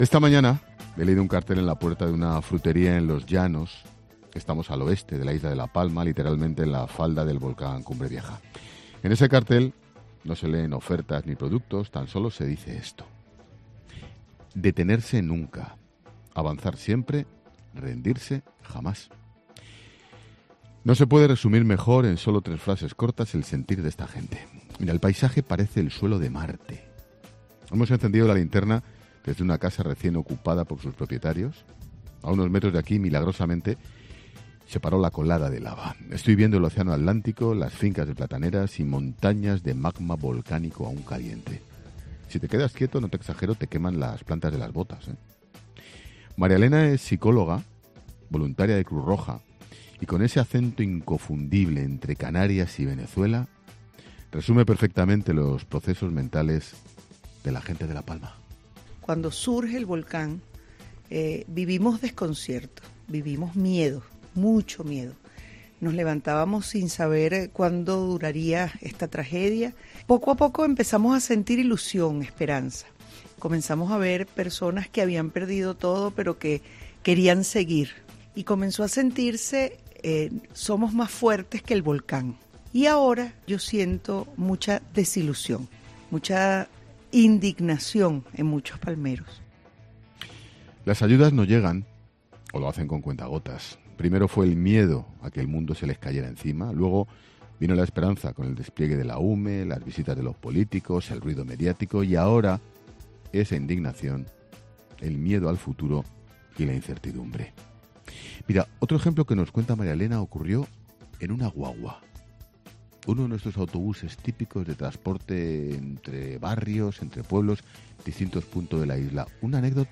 Ángel Expósito, desde La Palma: "El paisaje parece el suelo de Marte"
Monólogo de Expósito